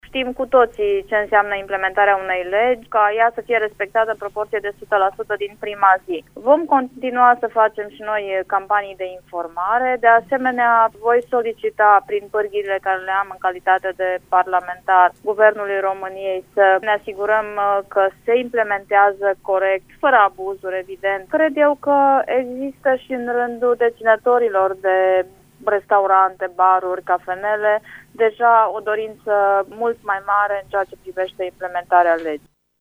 Legea care interzice fumatul în spaţiile publice închise, promulgată astăzi de preşedintele Klaus Iohannis, trebuie respectată de toată lumea, dar, în acelaşi timp, cei care o aplică trebuie să se asigure că nu se comit abuzuri, a susţinut, astăzi, în emisiunea „Probleme la zi” de la Radio România Actualităţi, iniţiatoarea actului normativ, deputata social-democrată Aurelia Cristea: